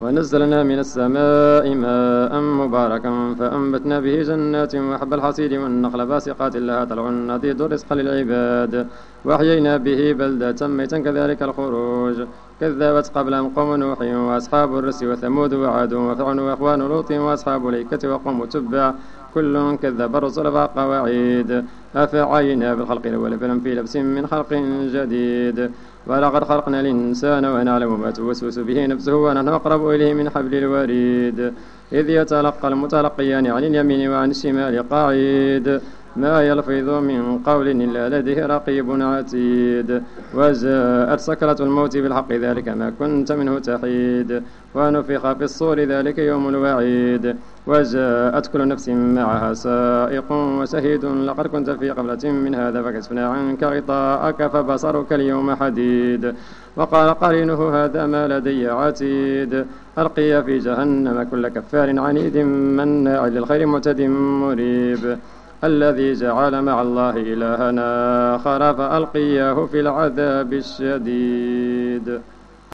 صلاة التراويح ليوم 25 رمضان 1431 بمسجد ابي بكر الصديق ف الزو
صلاة رقم 07 ليوم 25 رمضان 1431 الموافق سبتمبر 2010